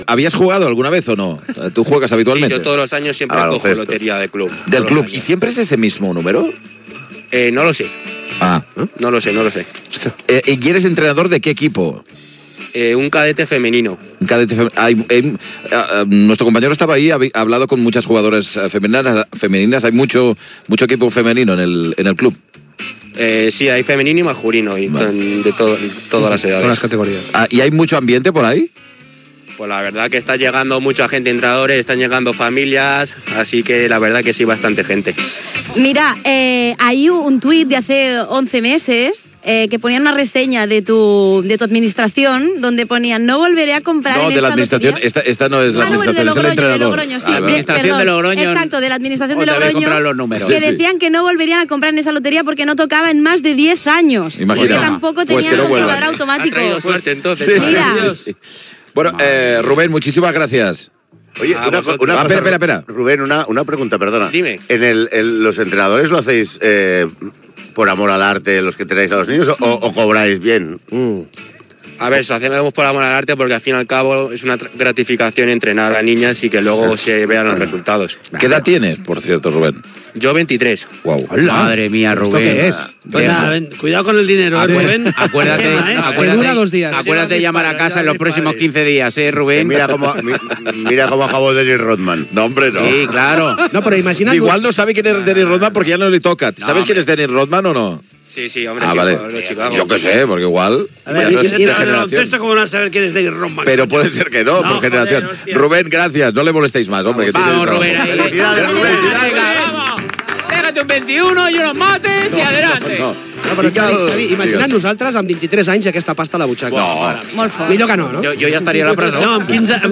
07485e0fffe872eb1d27e4d1e35bafd8e62d8619.mp3 Títol RAC 1 Emissora RAC 1 Barcelona Cadena RAC Titularitat Privada nacional Nom programa Via lliure Descripció Especial de la rifa de Nadal. Connexió amb Madarid on un club de bàsquet ha venut participacions del primer premi de la rifa de Nadal, connexió amb Sant Boi on una administració ha venut un cinquè premi de la rifa, publicitat, indicatius del programa i de la ràdio, publicitat, indicatiu del programa, comentaris i publicitat, informació des del Teatro Real de Madrid on s'està fent el sorteig on un espectador disfressat s'ha desmaiat